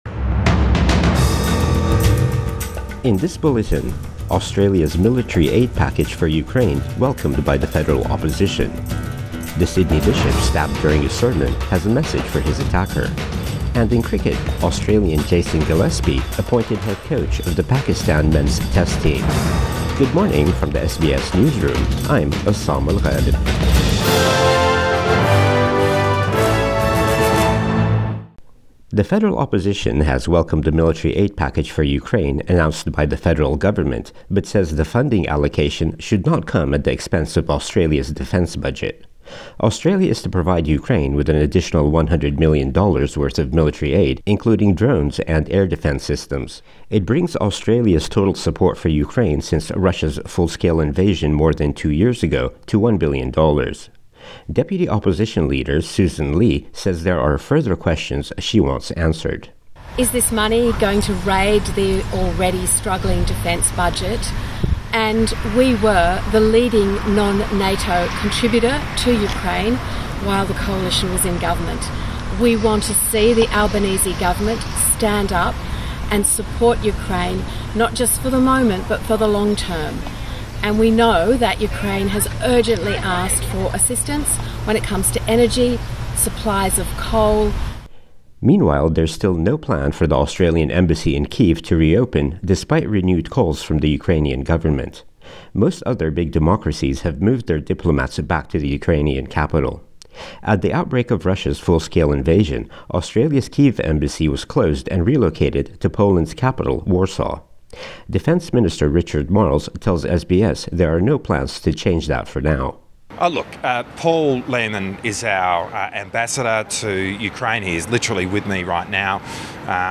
Morning News Bulletin 29 April 2024